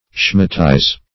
Schematize \Sche"ma*tize\, v. i. [Cf. F. sch['e]matiser, Gr. ?.]